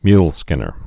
(mylskĭnər)